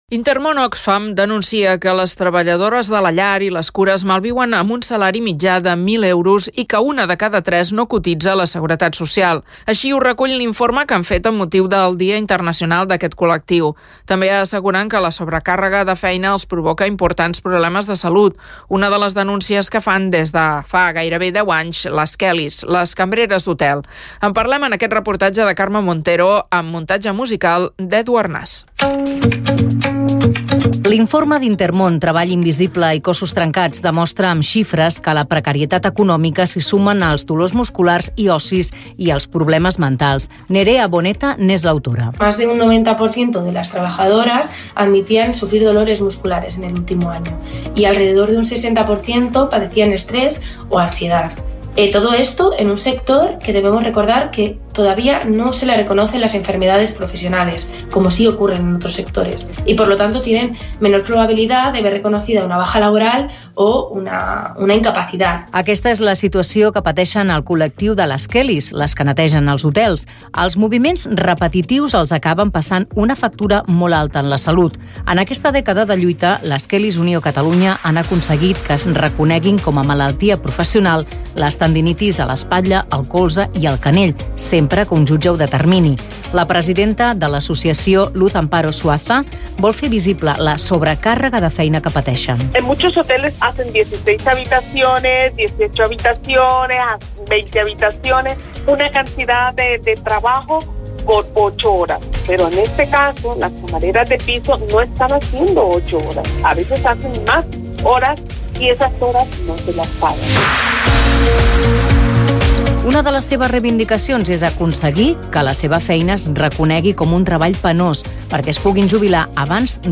Crònica Kellys - Cat Info, 2025